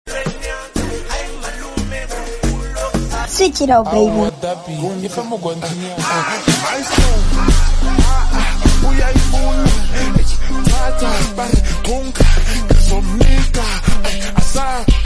Long time no Amapiano 😩🔥..